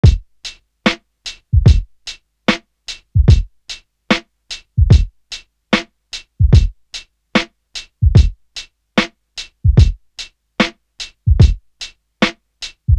Pointer Drum.wav